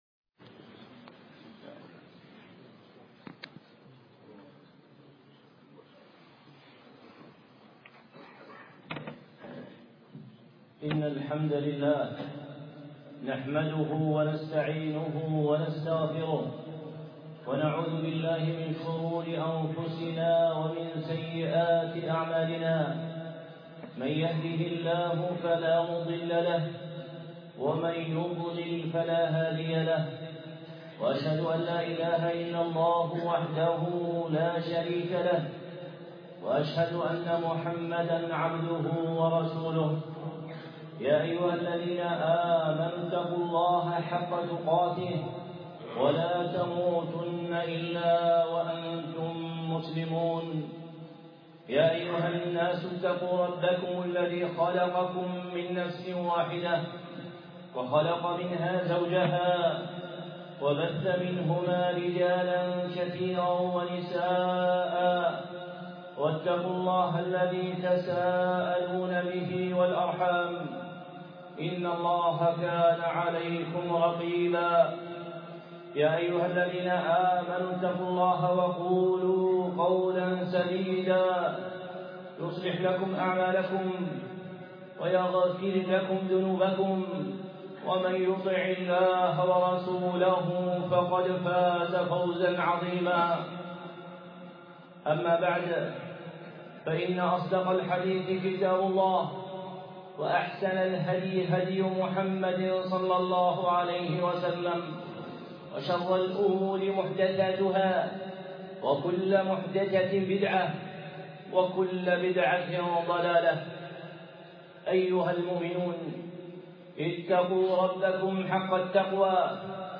خطبة إلباس الحق بالباطل في مسألة الحجاب